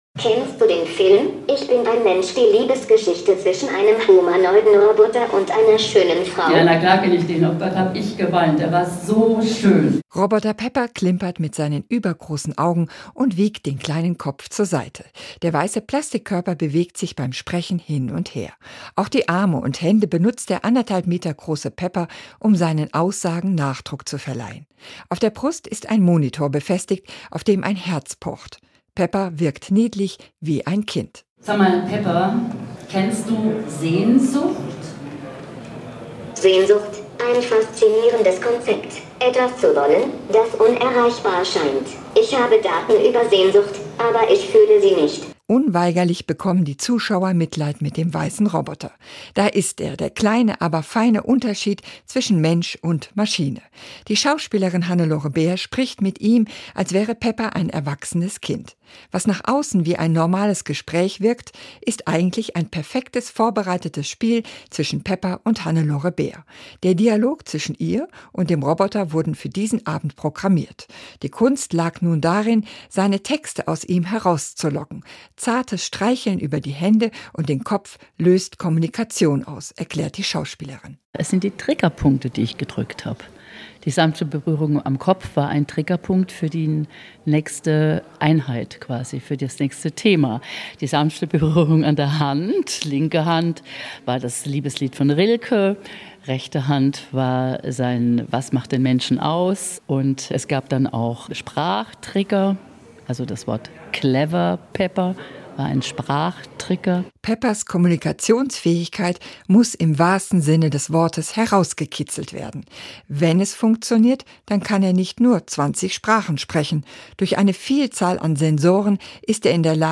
Performance mit Pflege-Roboter Pepper
Er ist so groß wie ein sechsjähriges Kind, kann sich bewegen und sprechen: der Roboter Pepper ist eigentlich für die Pflege gedacht.
roboter-pepper-kaiserslautern42.mp3